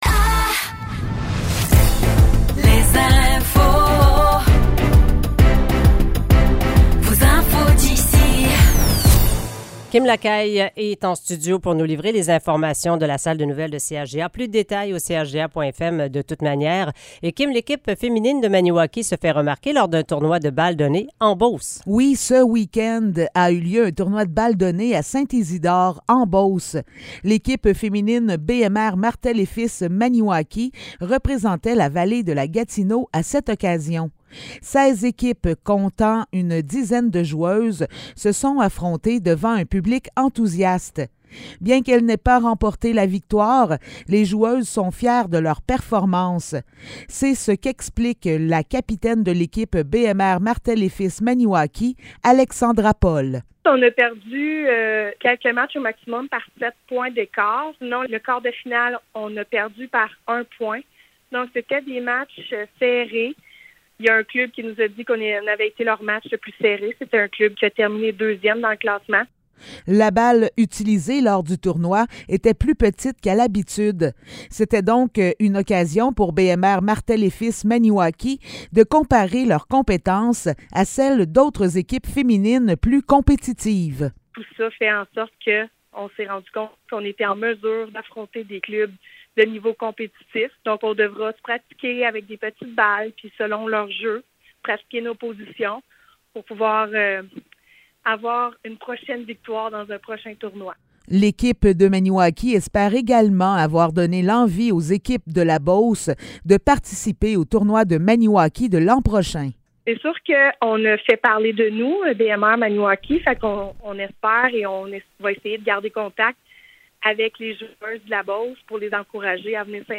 Nouvelles locales - 19 septembre 2023 - 7 h